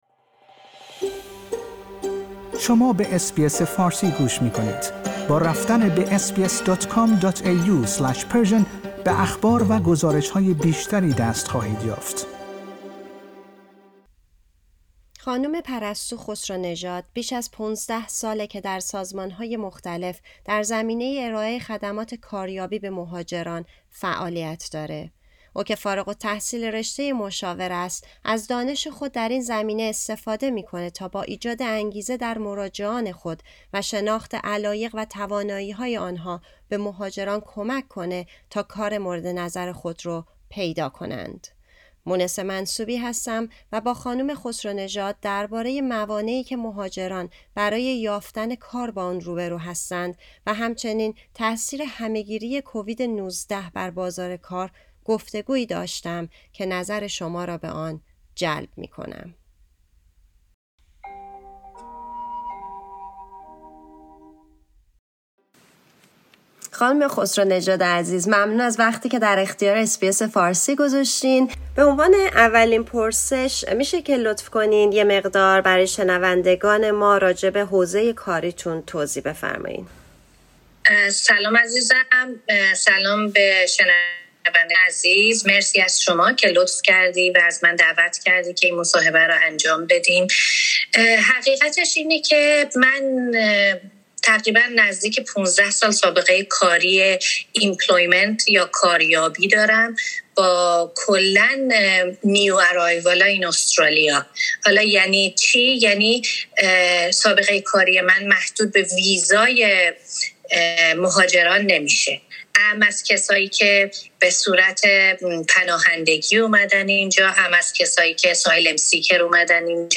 در این گفتگو